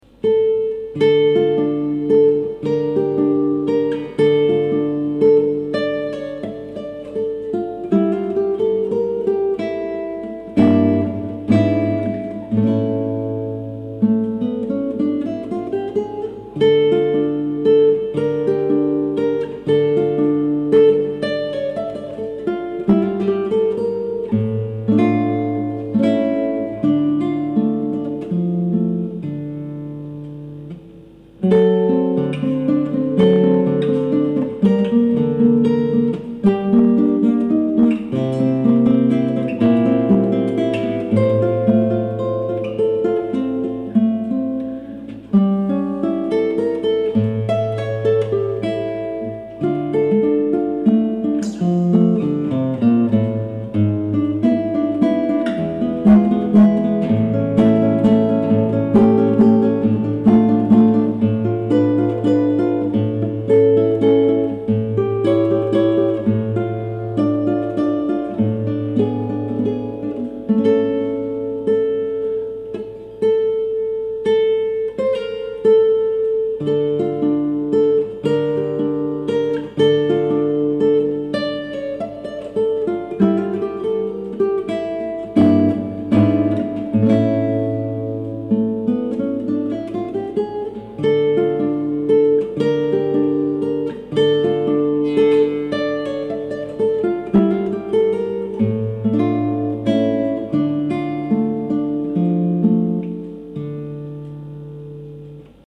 Andante de Napoleon Coste - Guitare Classique
Voici une pièce de Napoleon Coste enregistré sur le vif c'est après midi,pas très travaillée mais elle me plaisait alors :mrgreen:
On dirait que tu as fait la même chose que moi sur mes derniers enregistrements, au niveau des clicks audio :mrgreen:
Pour le son j'utilise juste audacity avec un poil de reverb.
J'ai aussi une guitare qui a son plutot clair avec les cordes adequat ensuite il ya mes ongles que je lime d'une certaine façon pour avoir une epaisseur et un fil adapté qui donne un peu ce type de sonnorité.
Pour l'enregistrement je me positionne dans la pièce là    ou je sensque l'acoustique est la meilleure enfin des petites choses qui aident beaucoup.